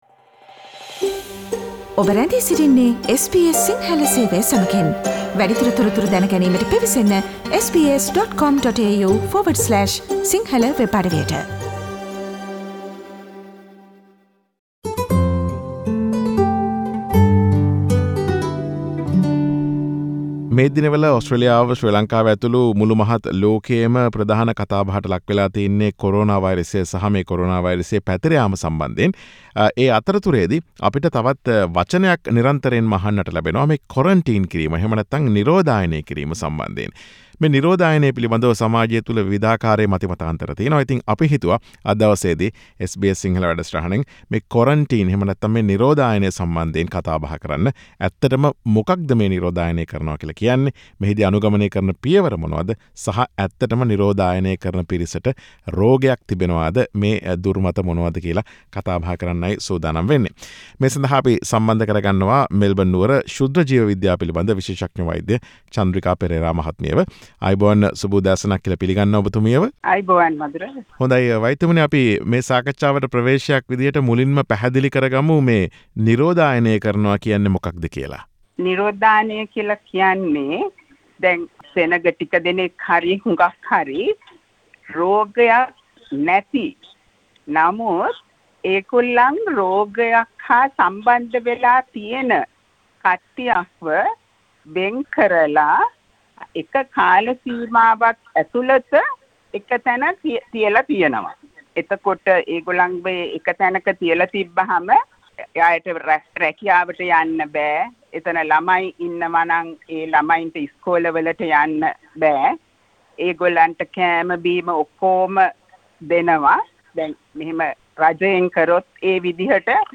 SBS සිංහල ගුවන් විදුලිය සිදුකළ සාකච්ඡාව.